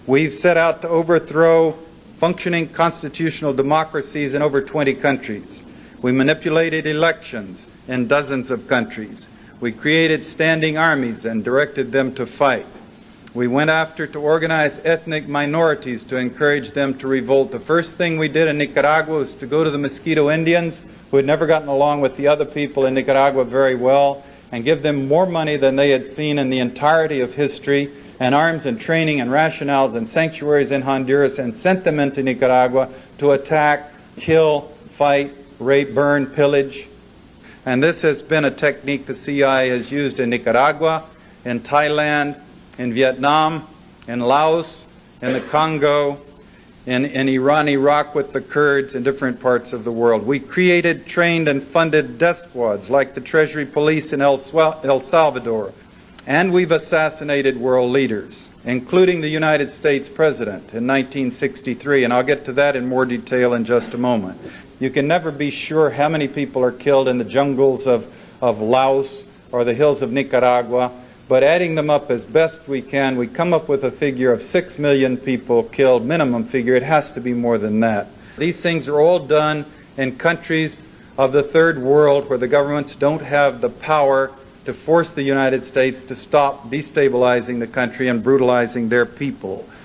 John Stockwell, a former CIA Operations Director, at American University 1989. There he talked about the inner workings of the CIA.